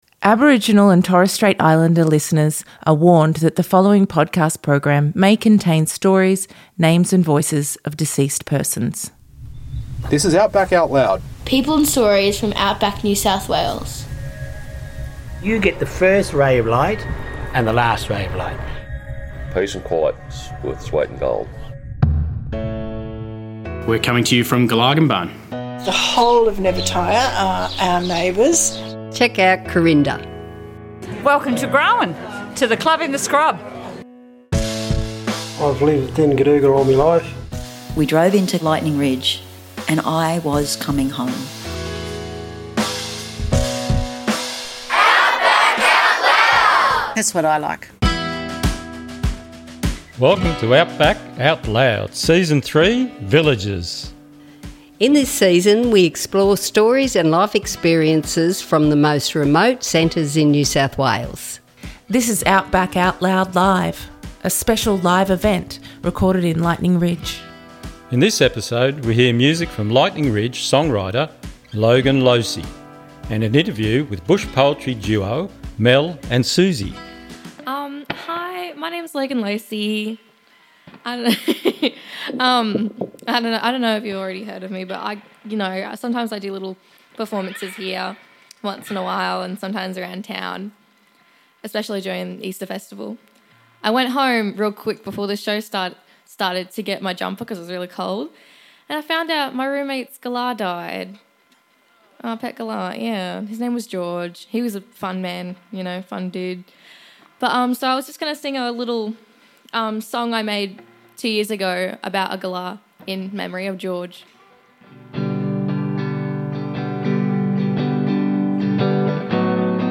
S3 Ep13: LIVE! Lightning Ridge Part 2 28:04 Play Pause 5d ago 28:04 Play Pause Later Afspelen Later Afspelen Lijsten Vind ik leuk Leuk 28:04 In this special episode from Lightning Ridge we bring you the release of our live event held at the Tin Camp Studios and Hungry Spirit in the Ridge during September 2024.